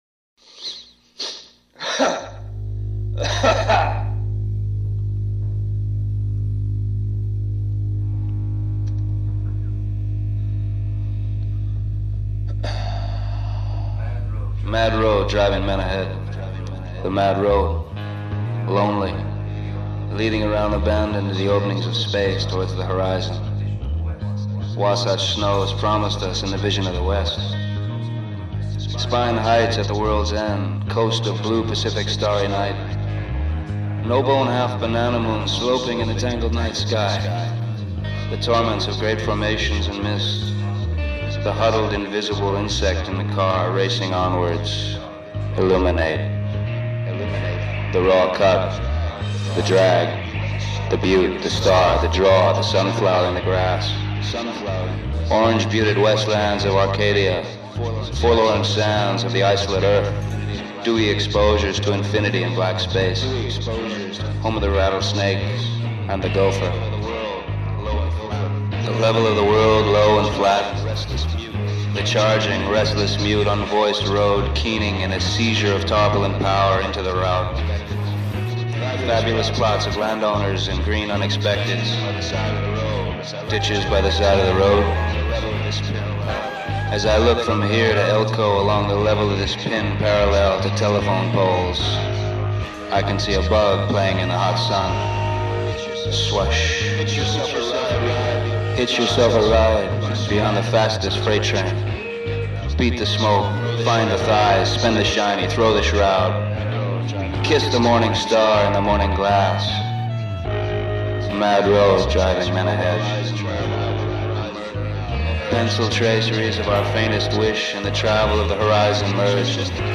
Johnny Depp reading Jack Kerouac